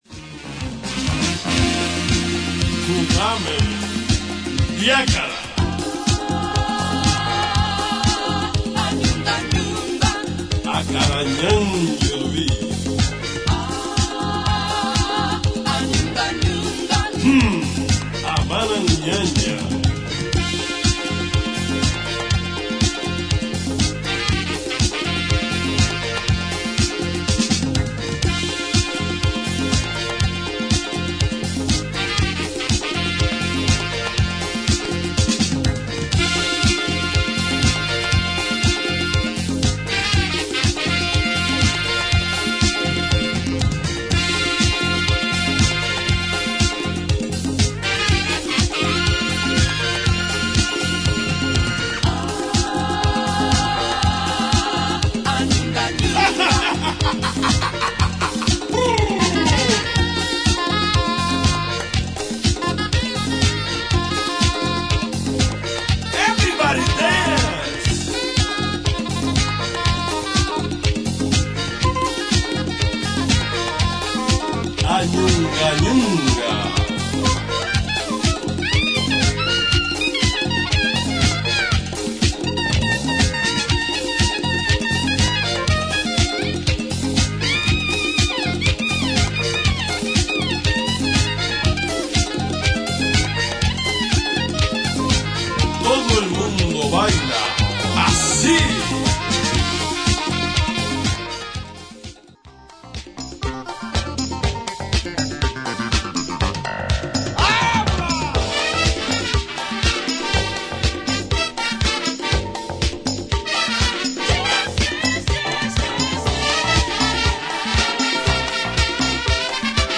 パーカッシブなディスコ・ビートに絡むホーン・セクションが否が応でもテンションを上げてくれる
リマスターされて音質が良くなっているのもナイスです。